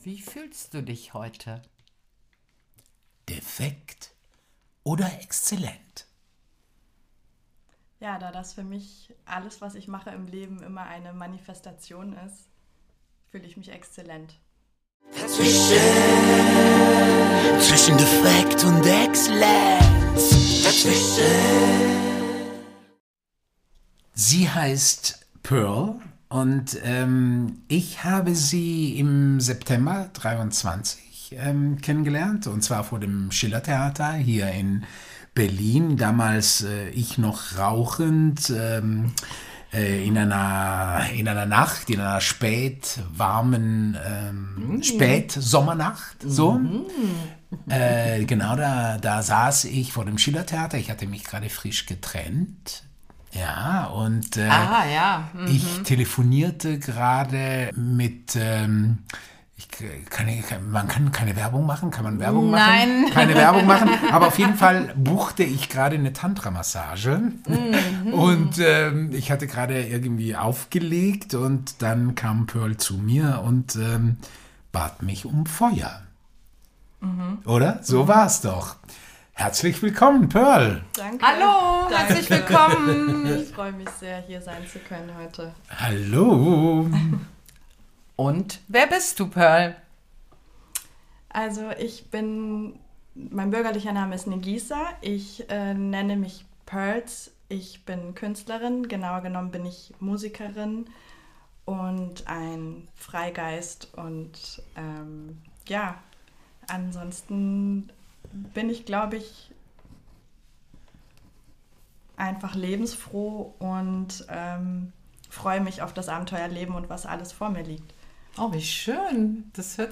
Sie produziert ihre Songs komplett independent im Home-Studio und ist ihre eigene Managerin. Es ist ein tolles Gespräch mit einer wunderbaren Frau.